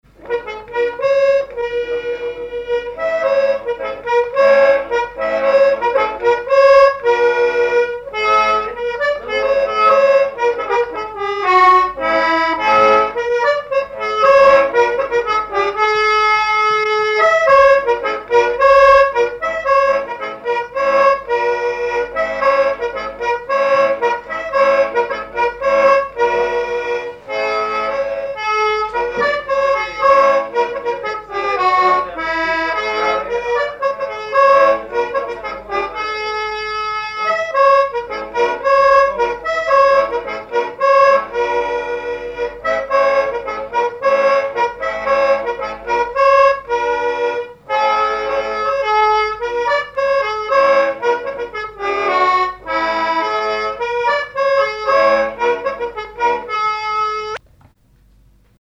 Genre laisse
accordéoniste
Pièce musicale inédite